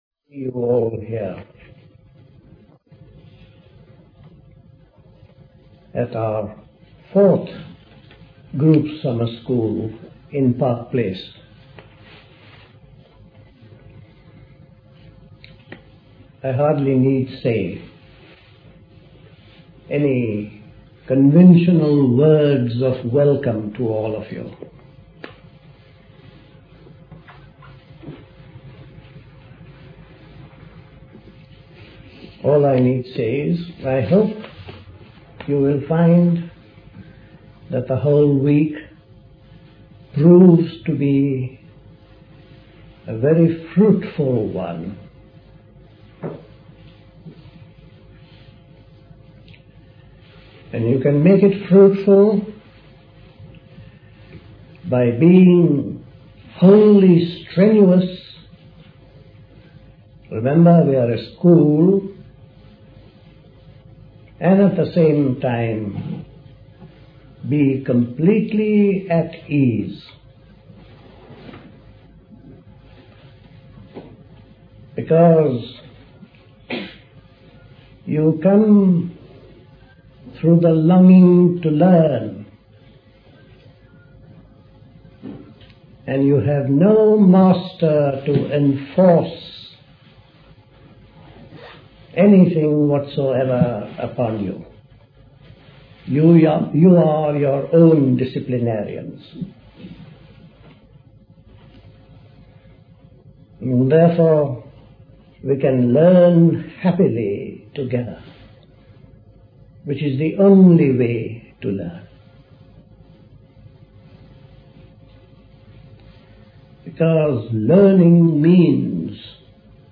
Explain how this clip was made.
at Park Place Pastoral Centre, Wickham, Hampshire on 16th May 1977